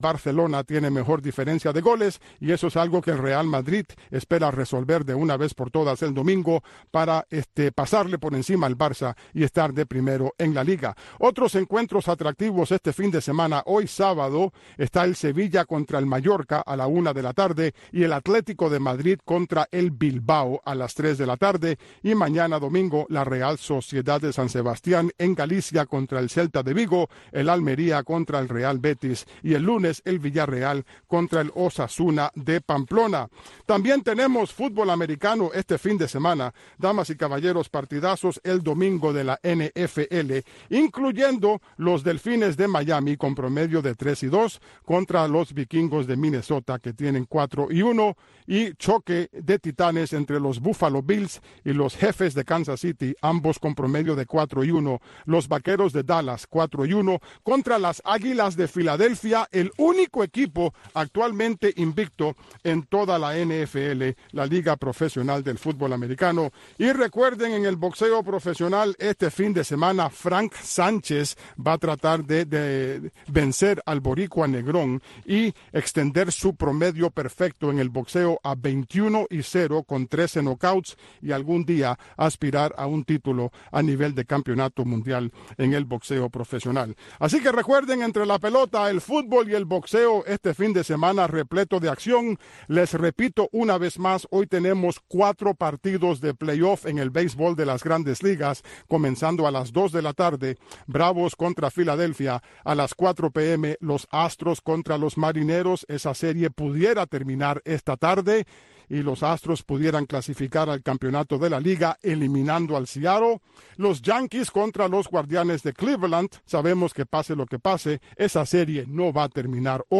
Noticiero Radio Martí presenta los hechos que hacen noticia en Cuba y el mundo.